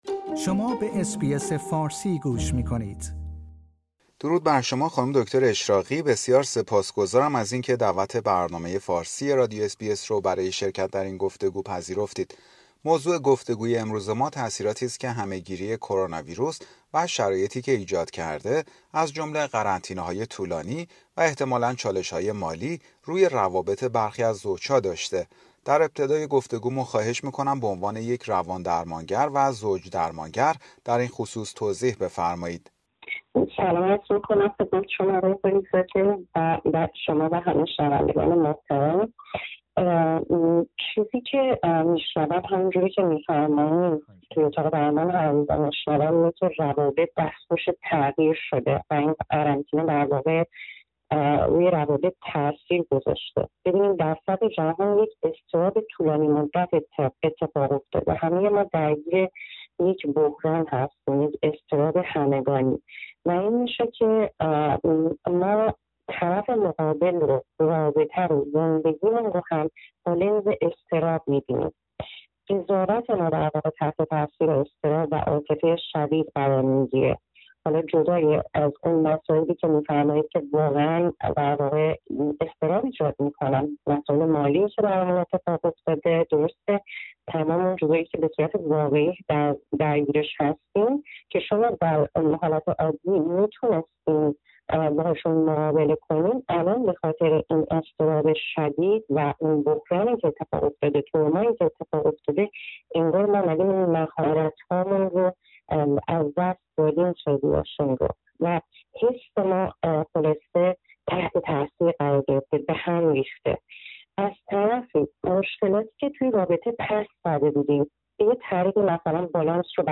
گفتگویی در مورد تاثیر شرایط ناشی از همه گیری کووید-۱۹ روی روابط بسیاری از زوج ها